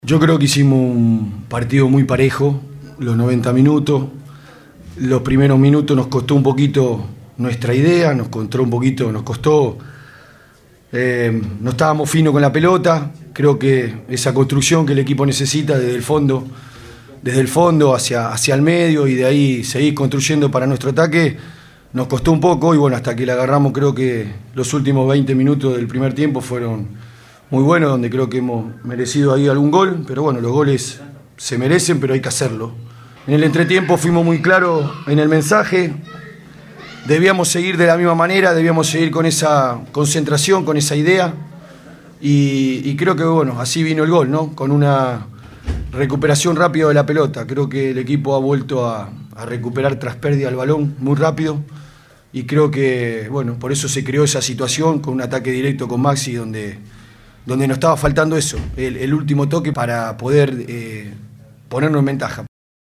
Al finalizar el partido